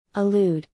But when you’re saying them slowly, the first syllable of “allude” has an “ah” sound, while the first syllable of “elude” has an “eh” sound.